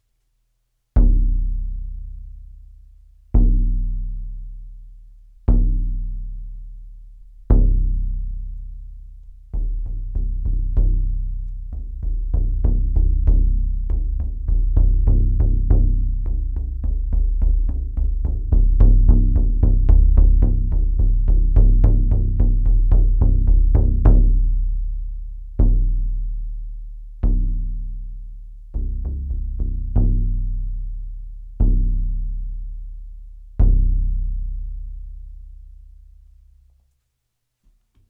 Tischtrommel, Powwow
Die Tischtrommel ist eine alte Ritualtrommel.
Ein massiver Holzrahmen mit naturbelassenem Ziegenfell bespannt.
Durchmesser der Trommel: 68 cm
Klangbeispiel Tischtrommel